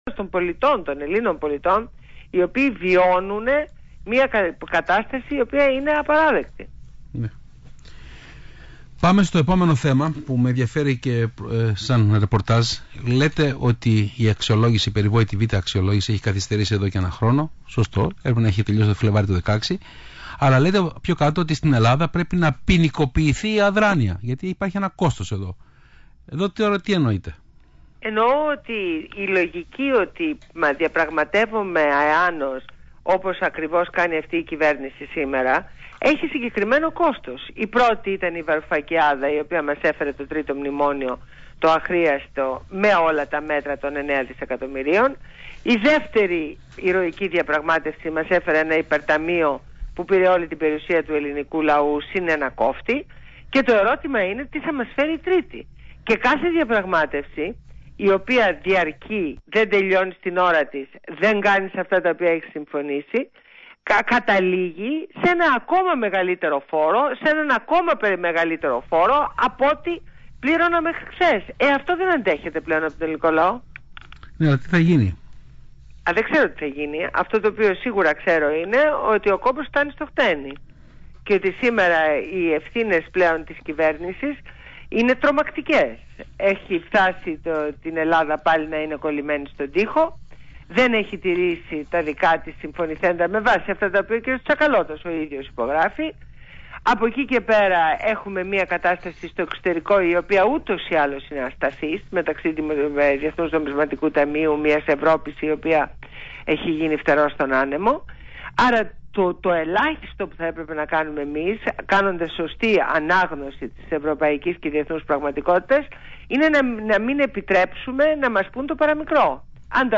Ακούστε τη συνέντευξη στο ACTION24